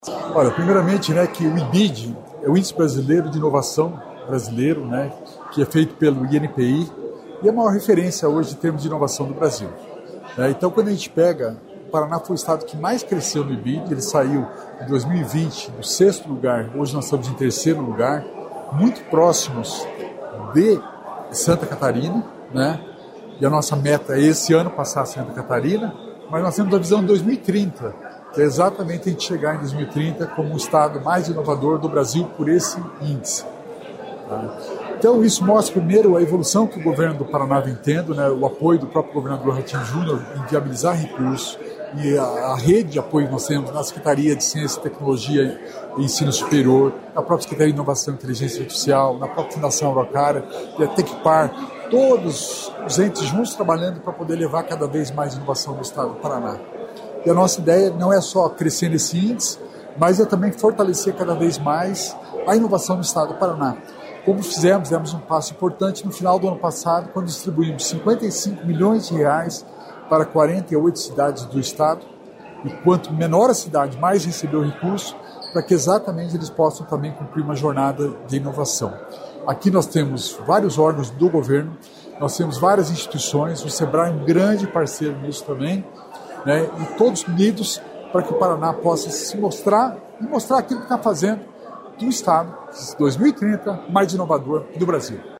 Sonora do secretário Estadual da Inovação e Inteligência Artificial, Alex Canziani, sobre o lançamento da Jornada IBID 2030